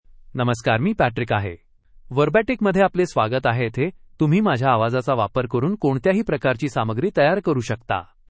MaleMarathi (India)
Patrick — Male Marathi AI voice
Voice sample
Male